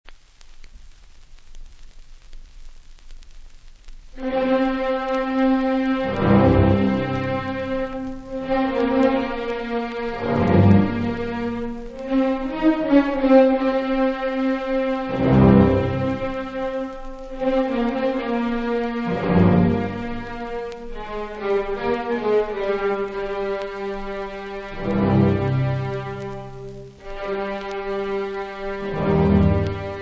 • Berlin Opera Orchestra [interprete]
• Melichar, Alois [direttore d'orchestra]
• rapsodie
• Rhapsody
• registrazione sonora di musica